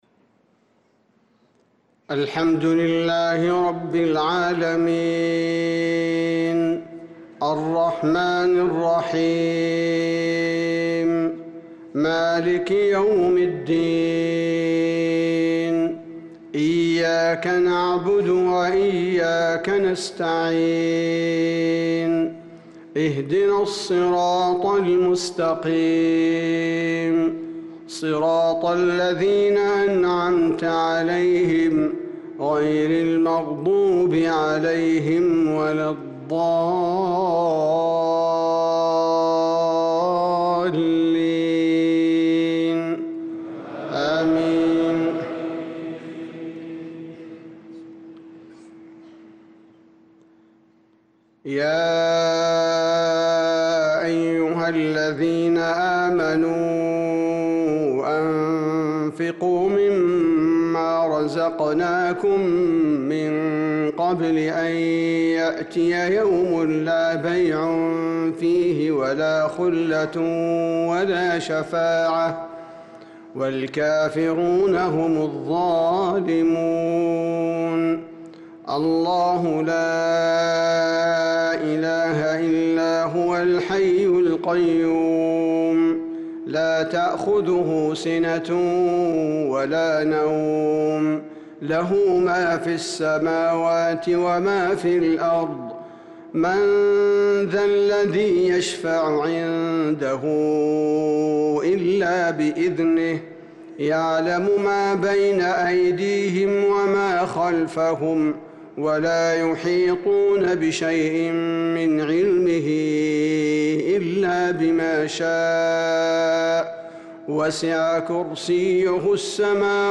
صلاة الفجر للقارئ عبدالباري الثبيتي 17 ذو القعدة 1445 هـ
تِلَاوَات الْحَرَمَيْن .